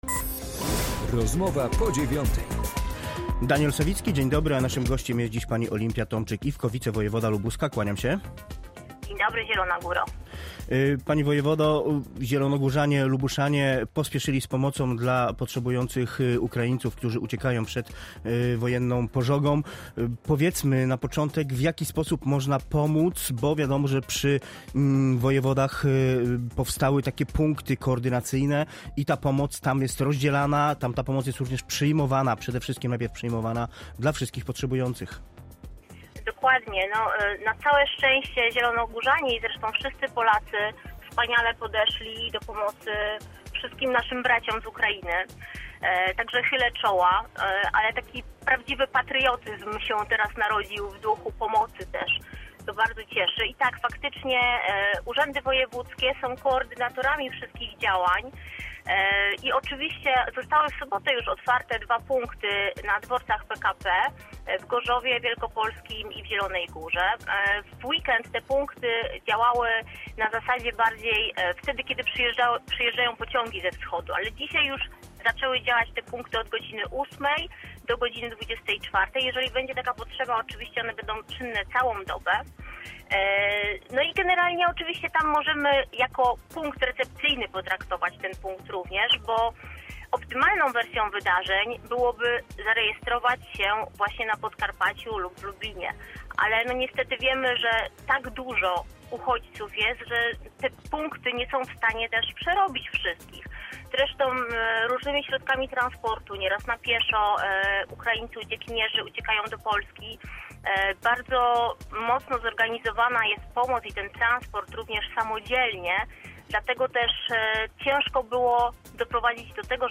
Z wicewojewodą rozmawia